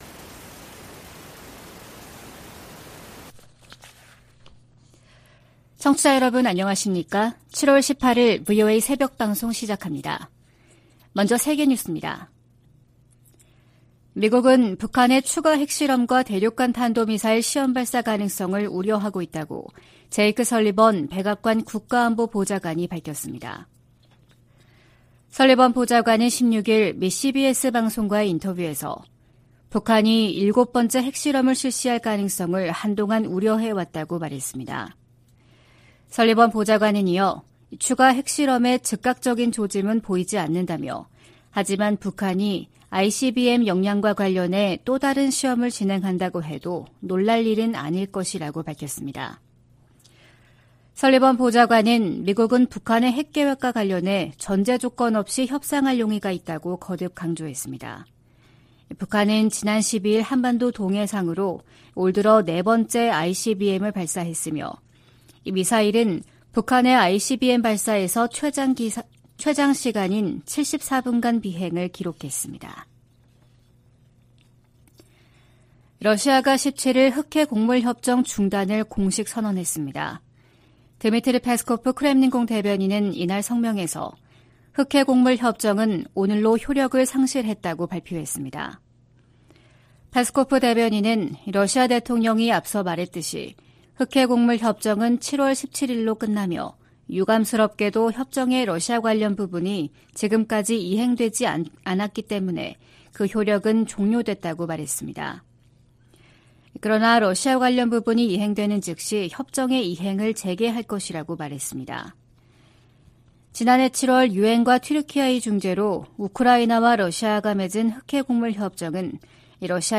VOA 한국어 '출발 뉴스 쇼', 2023년 7월 18일 방송입니다. 김여정 북한 노동당 부부장이 담화를 내고 대륙간탄도미사일 '화성-18형' 발사의 정당성을 주장하면서 미국을 위협했습니다. 인도네시아 자카르타에서 열린 제30차 아세안지역안보포럼(ARF) 외교장관회의에서 미한일 등 여러 나라가 북한의 탄도미사일 발사를 규탄했습니다. 미 상원에서 한국 등 동맹국의 방위비 분담 내역 의회 보고 의무화 방안이 추진되고 있습니다.